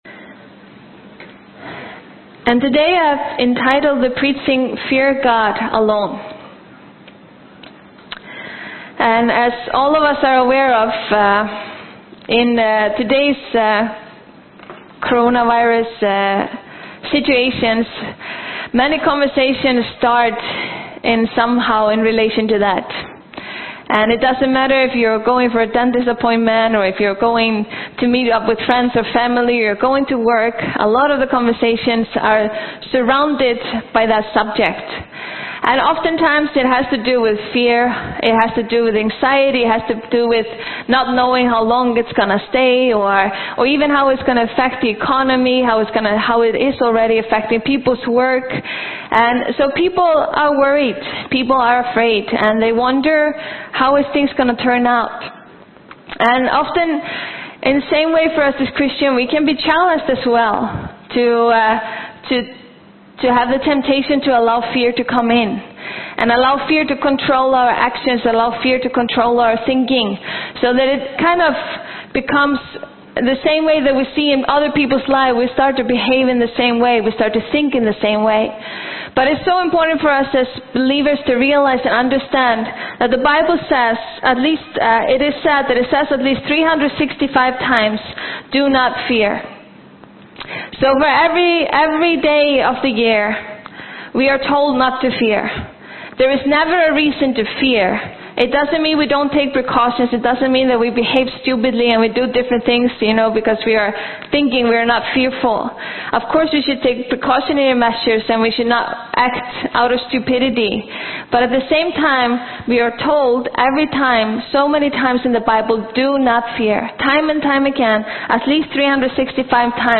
Sermon
10am service